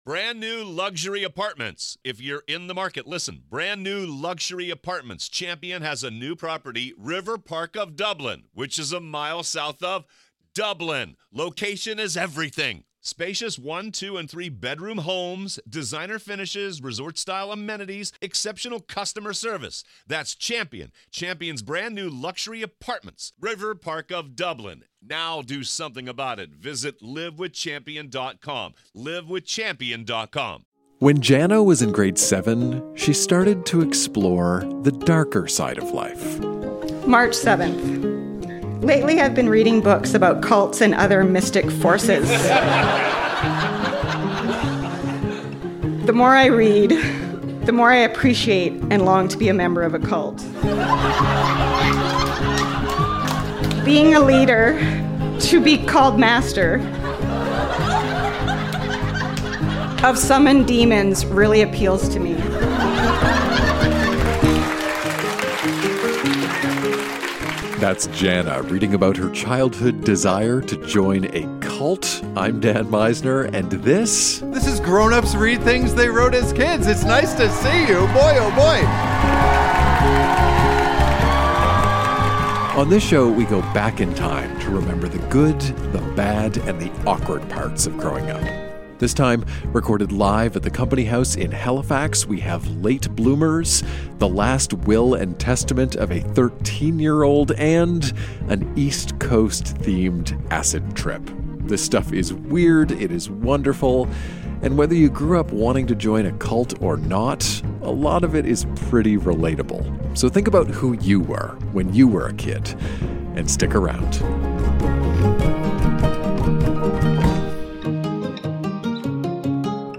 Recorded live at The Company House in Halifax, NS.